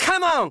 File:Fox voice sample SSBM.oga
Fox_voice_sample_SSBM.oga.mp3